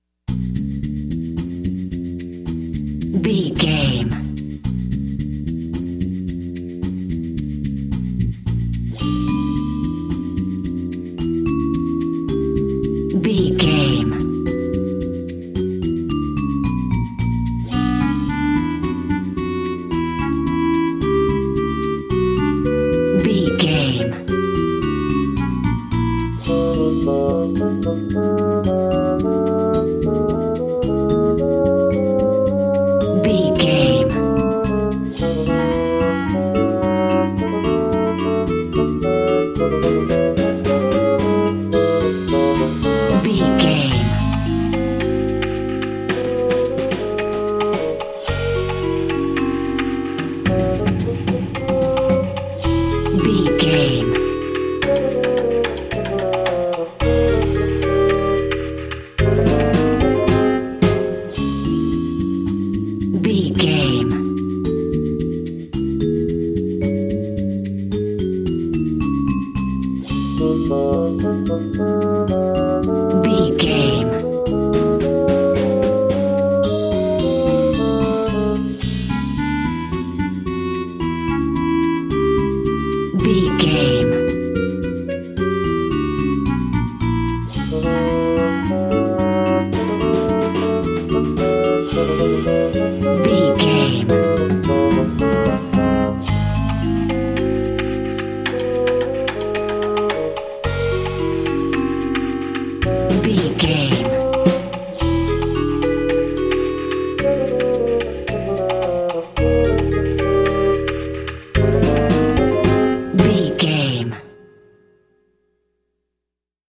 Aeolian/Minor
C#
percussion
flute
bass guitar
silly
circus
goofy
comical
cheerful
perky
Light hearted
secretive
quirky